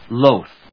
loath /lóʊθ, lóʊðlˈəʊθ/
• / lóʊθ(米国英語)